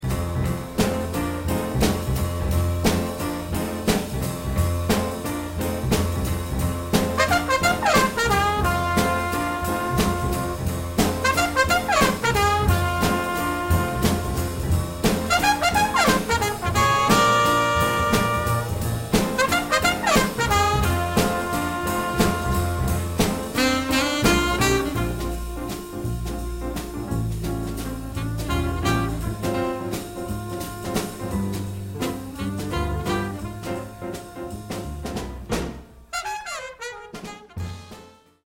soul-jazz sound